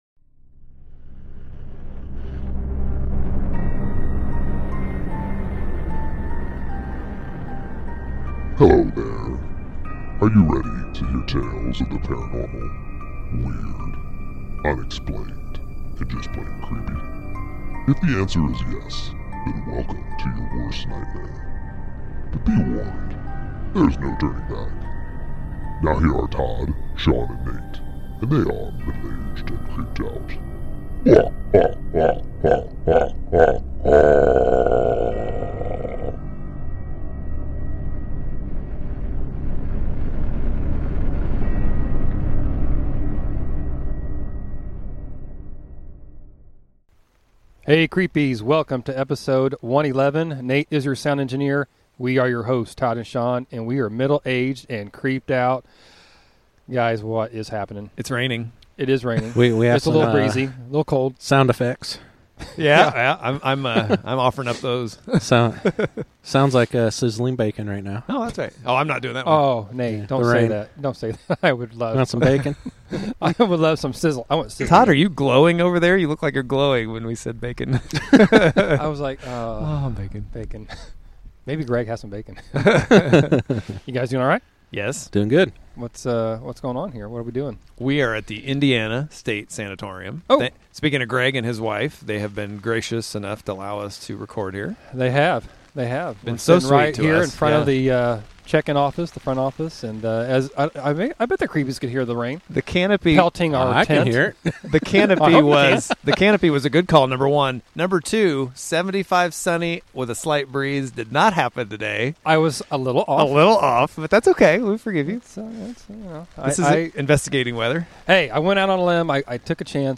Join us for a creepy live event (and treat)...our first "on location" at the ISS!!!